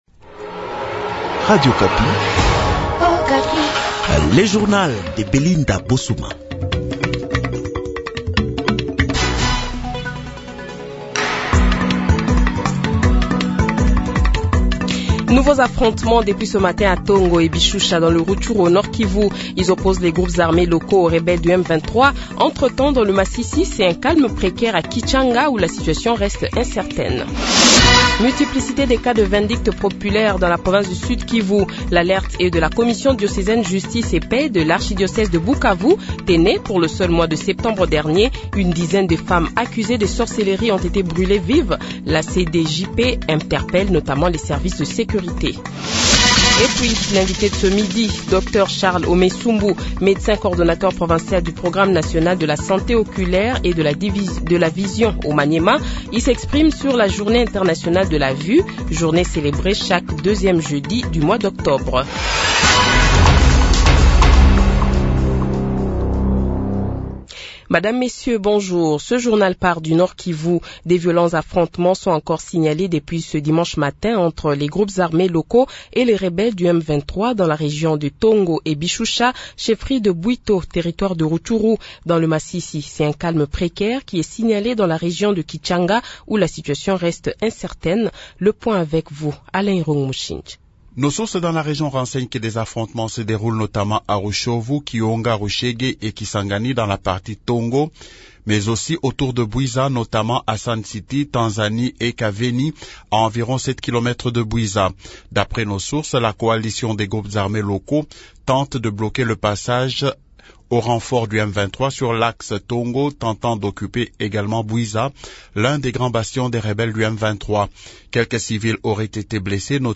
Le Journal de 12h, 15 Octobre 2023 :